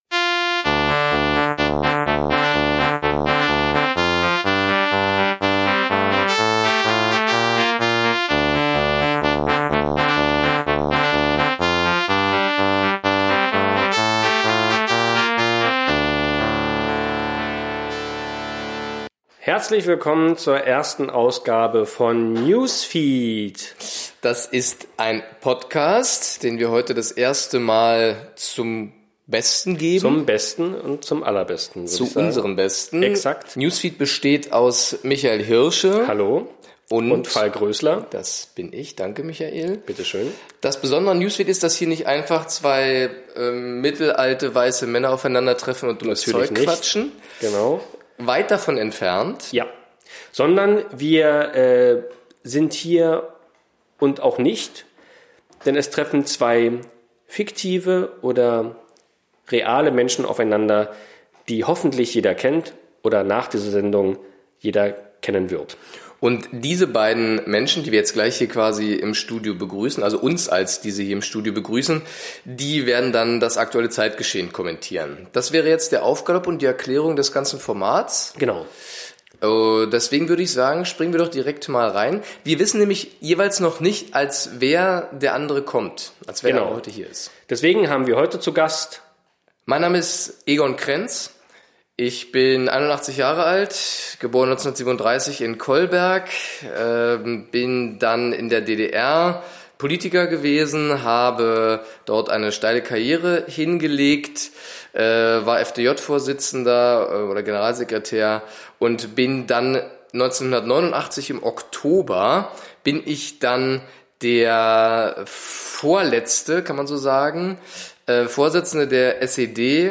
Die beiden Stars treffen vor dem Mikro zum ersten Mal aufeinander und kommentieren das aktuelle Zeitgeschehen. In der ersten Ausgabe begegnen sich Edgar Allan Poe und Egon Krenz.